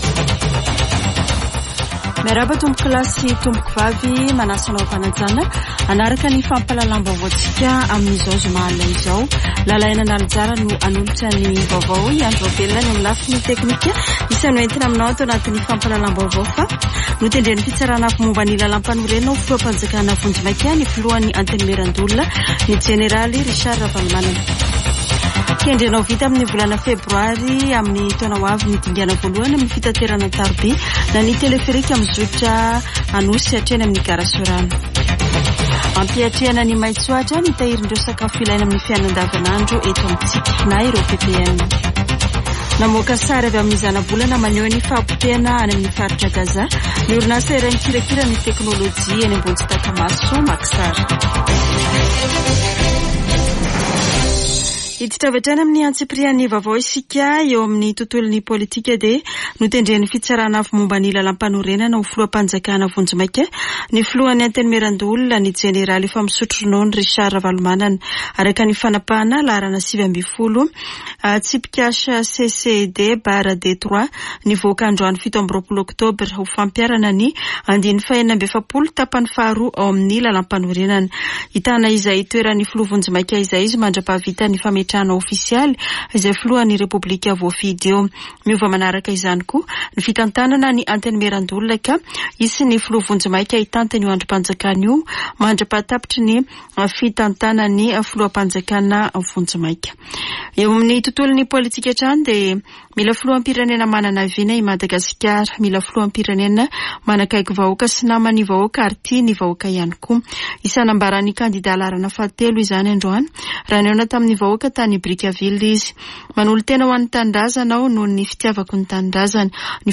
[Vaovao hariva] Zoma 27 ôktôbra 2023